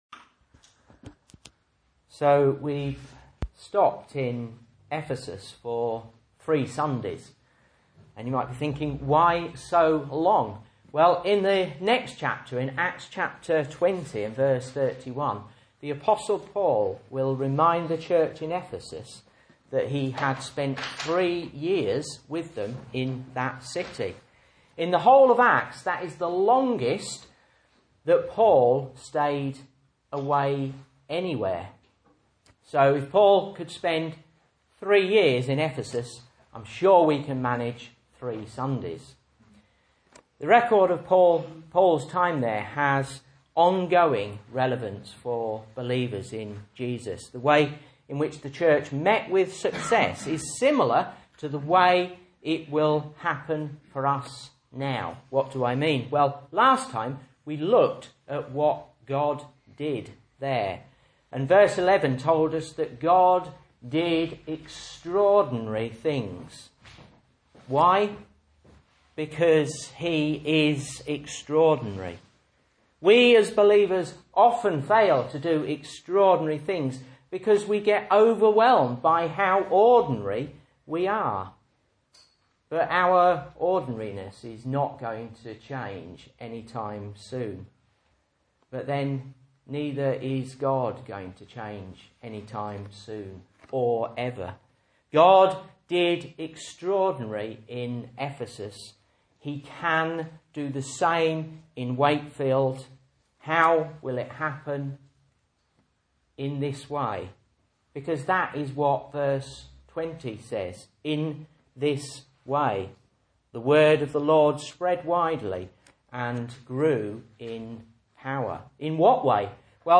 Message Scripture: Acts 19:23-41 | Listen